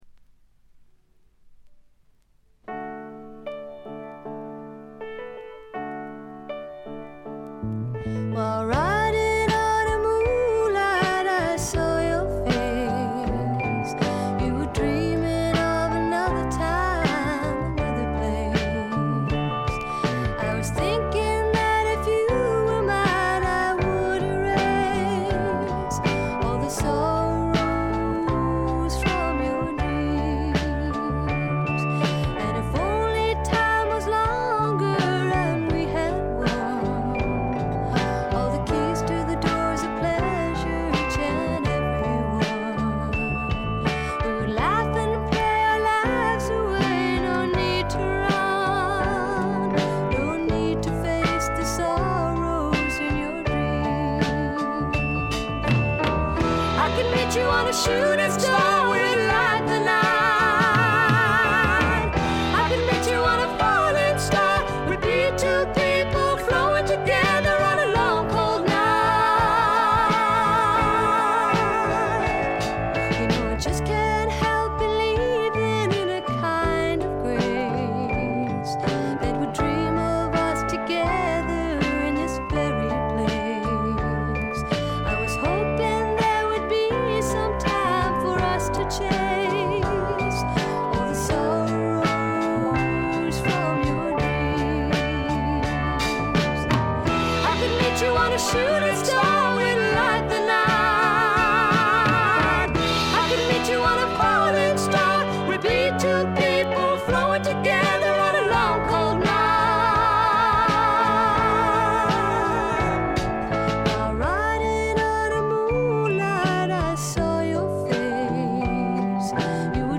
ホーム > レコード：英国 SSW / フォークロック
軽微なチリプチ少々。
静と動の対比も見事でフォークロック好きにとってはこたえられない作品に仕上がっています！
試聴曲は現品からの取り込み音源です。